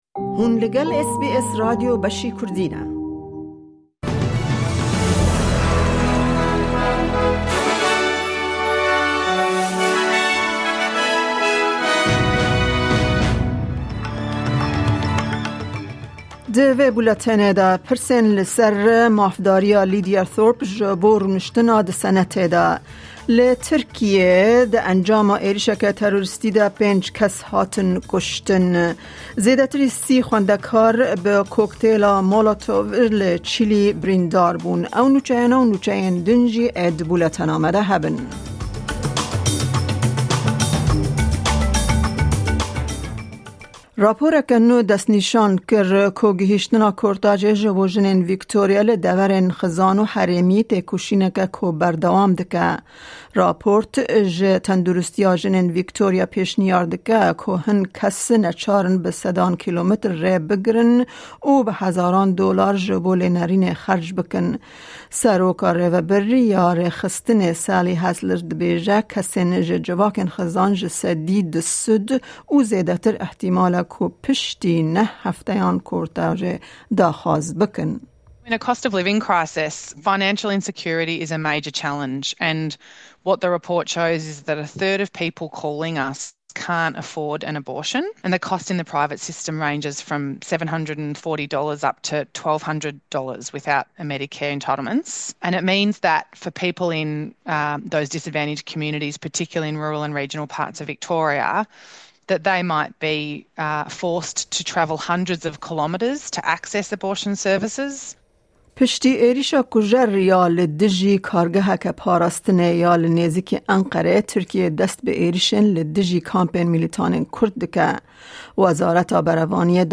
Kurdish News